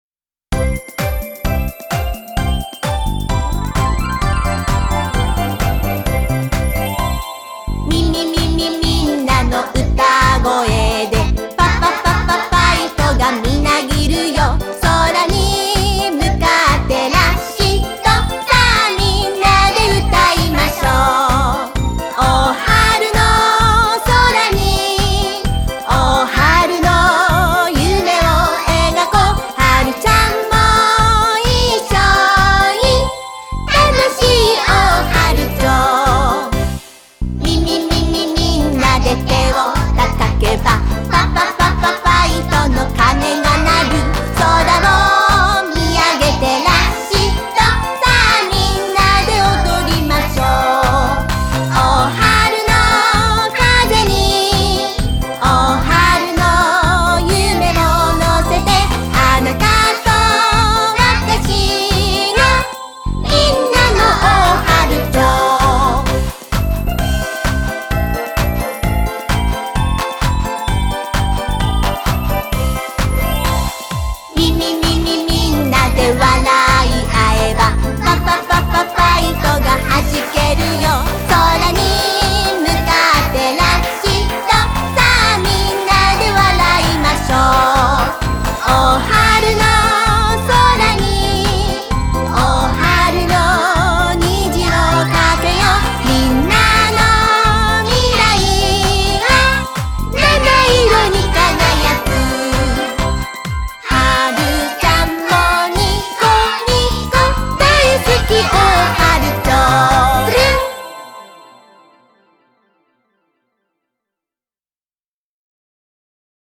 2曲とも、アップテンポで耳馴染みが良く、口ずさみたくなる楽しい曲となっています。
美しくのびやかな歌声も披露してくださっています。
コーラス参加
園児の皆さんの元気いっぱいな歌声も一緒にお聞きください。